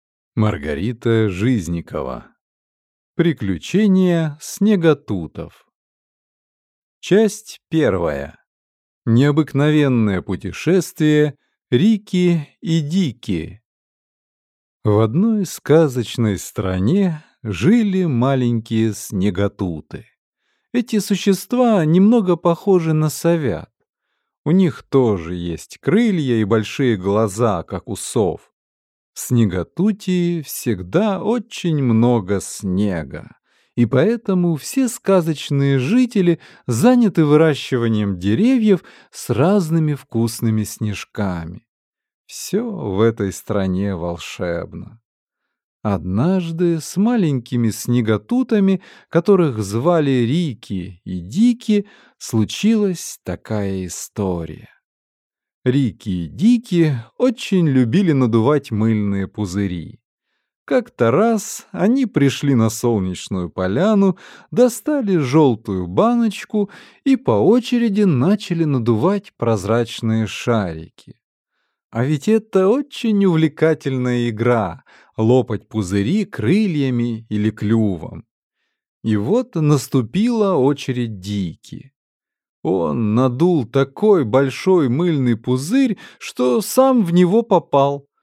Аудиокнига Приключения снеготутов | Библиотека аудиокниг
Прослушать и бесплатно скачать фрагмент аудиокниги